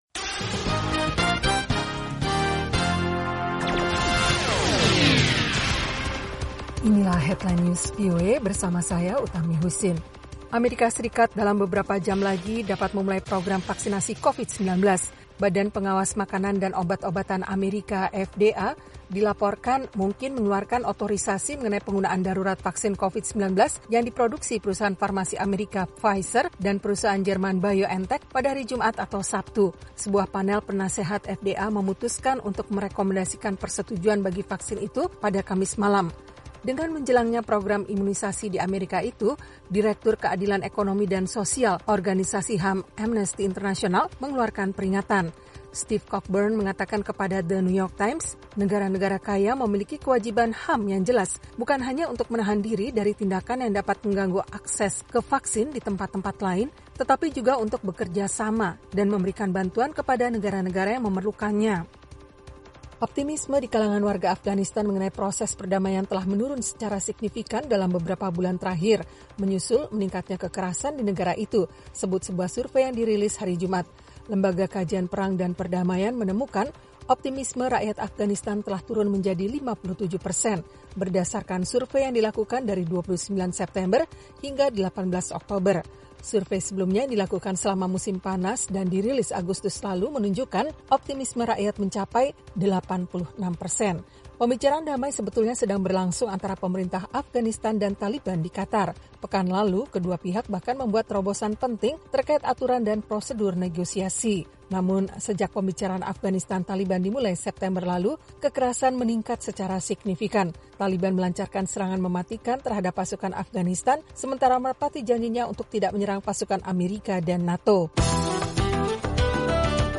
Simak berita terkini langsung dari Washington dalam Headline News, bersama para penyiar VOA, menghadirkan perkembangan terakhir berita-berita internasional.